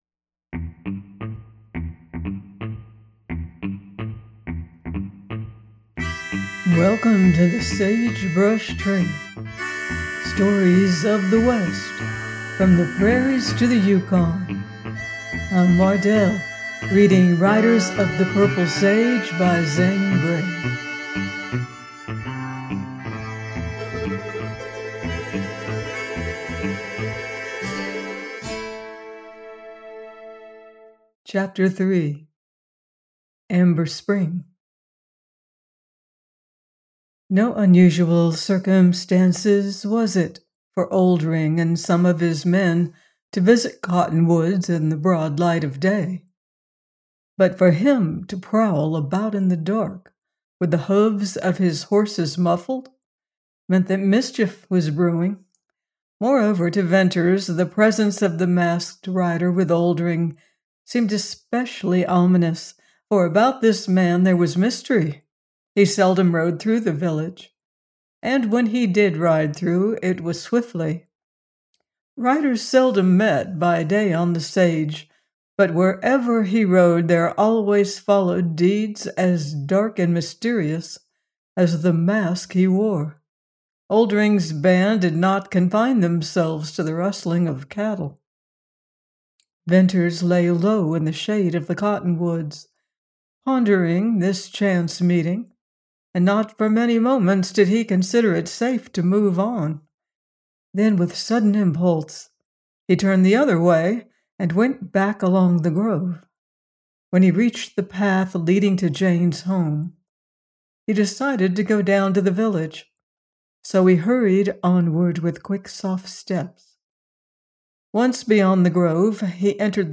Riders of the Purple Sage – Ch 3 : by Zane Grey - audiobook